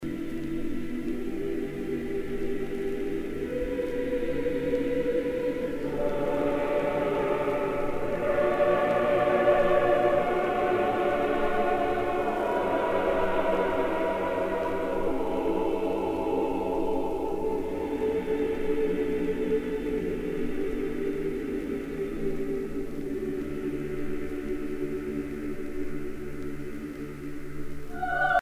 circonstance : dévotion, religion
Pièce musicale éditée